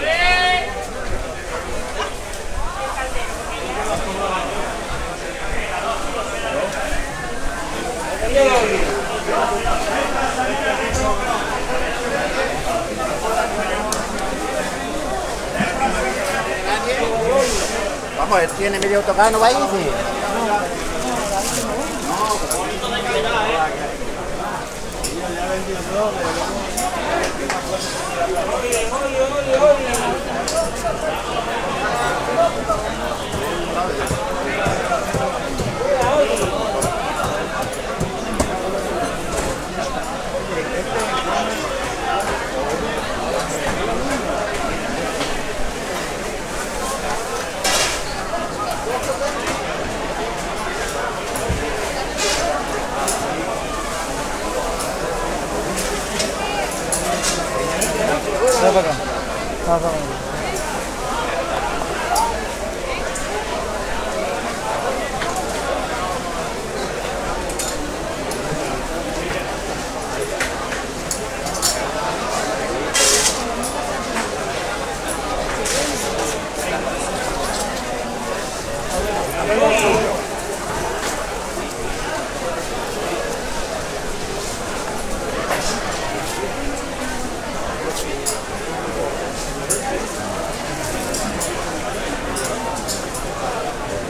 market-4.ogg